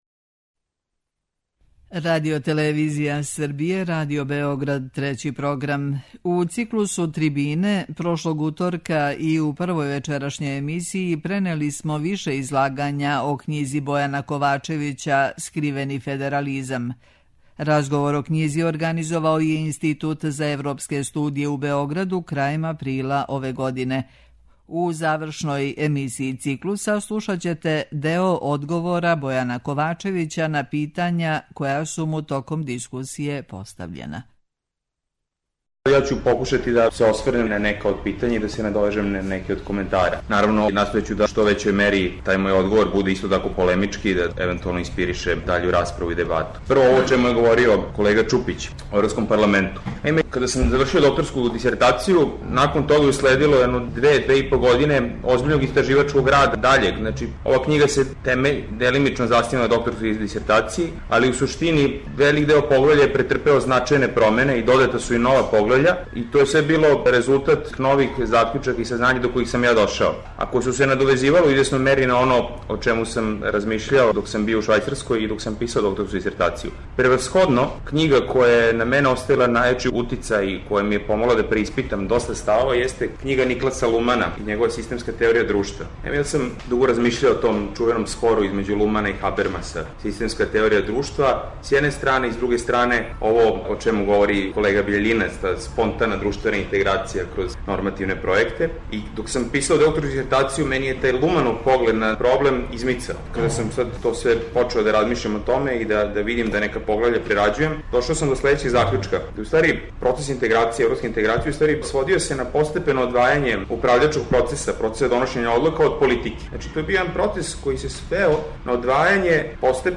Трибине